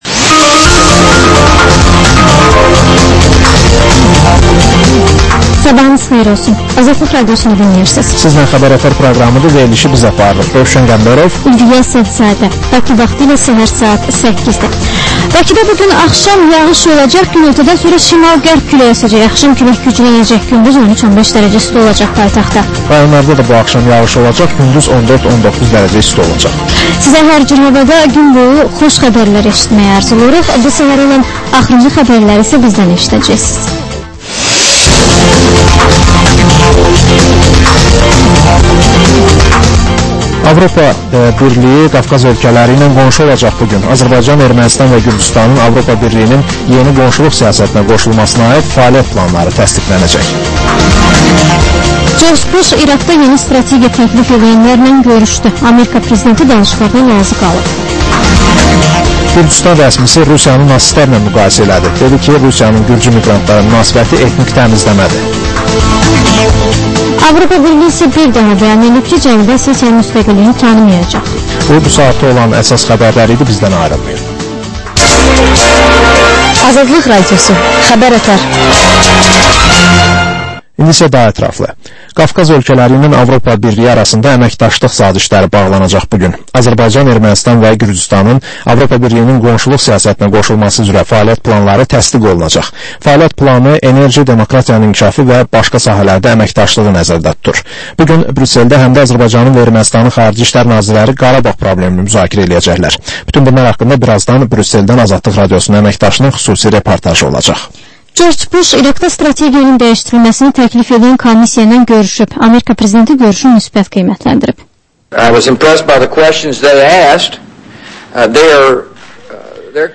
Səhər-səhər, Xəbər-ətər: xəbərlər, reportajlar, müsahibələr.